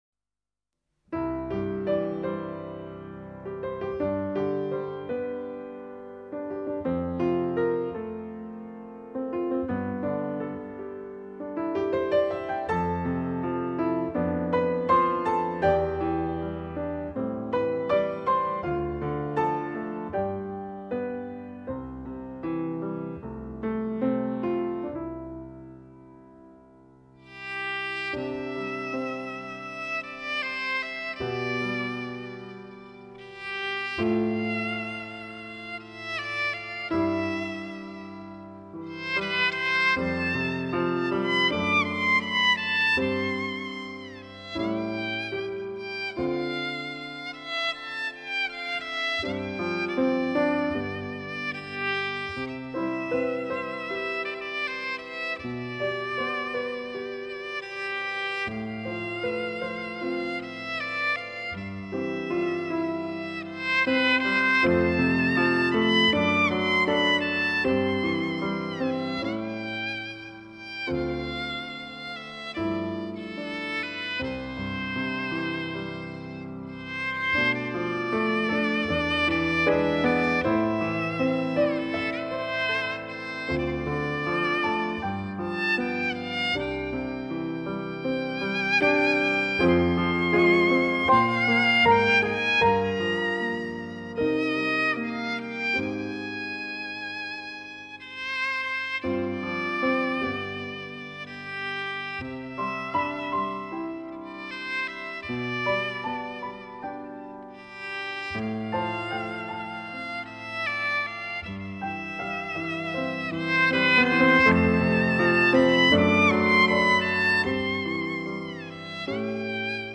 小心烧机